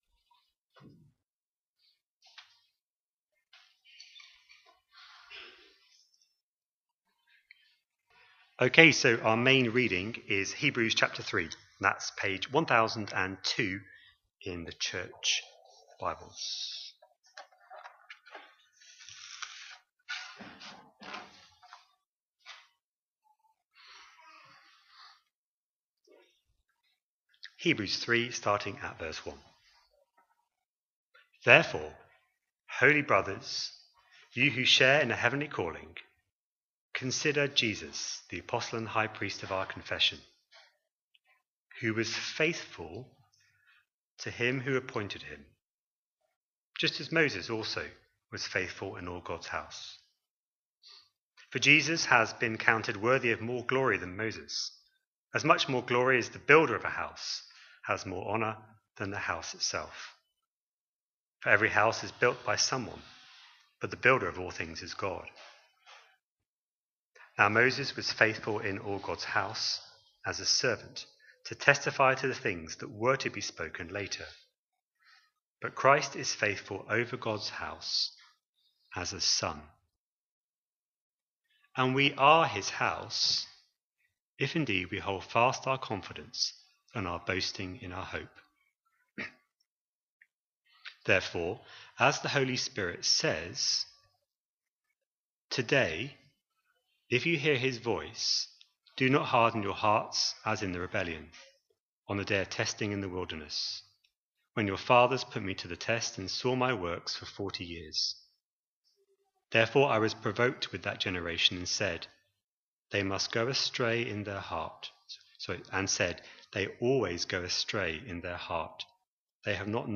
A sermon preached on 3rd November, 2024, as part of our Hebrews 24/25 series.